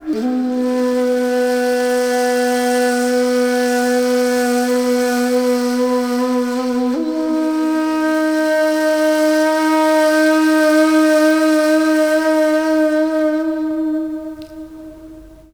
FLUT 01.AI.wav